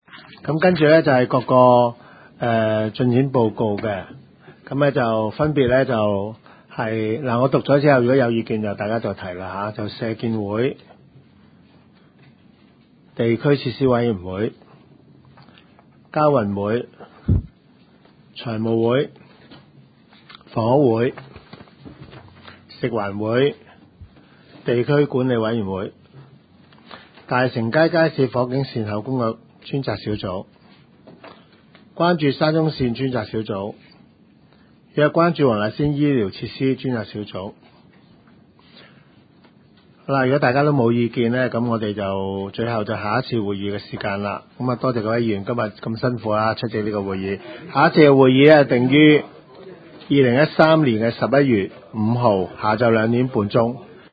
区议会大会的录音记录
黄大仙区议会会议室